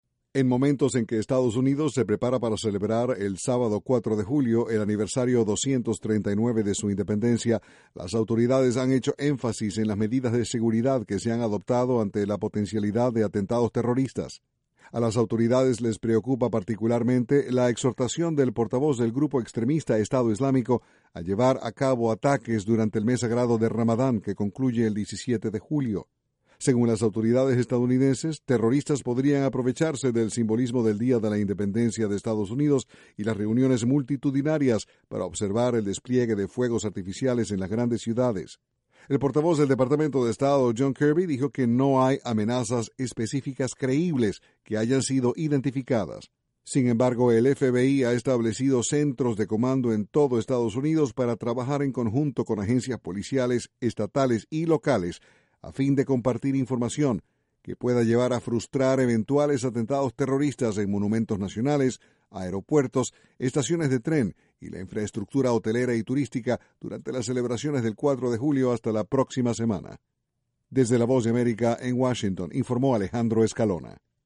Las autoridades estadounidenses redoblaron las medidas de seguridad dentro y fuera del país ante la posibilidad de ataques terroristas en el Dia de la Independencia. Desde la Voz de America, Washington